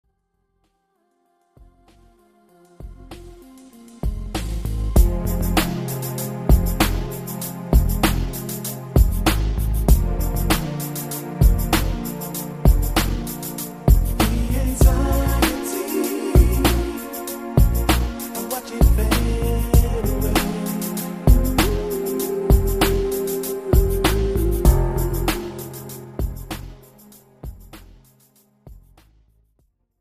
This is an instrumental backing track cover.
Key – F
With Backing Vocals
No Fade